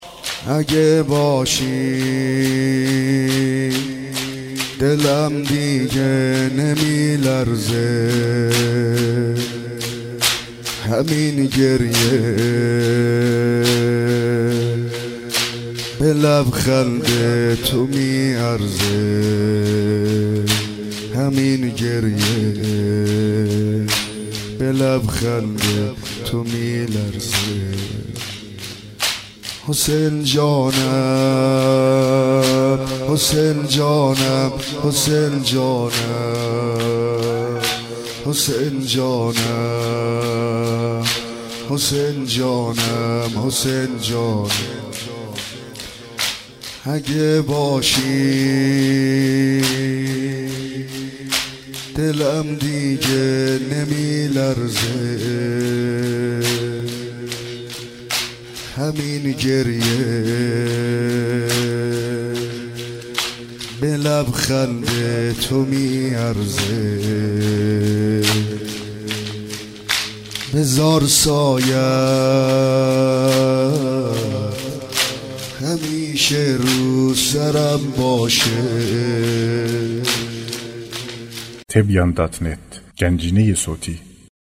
سینه زنی در شهادت حضرت فاطمه زهرا(س)